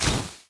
Media:ArcherQueen_super.wavMedia:ArcherQueen_super_hit.wav 技能音效 super 爆炸箭矢发射和命中音效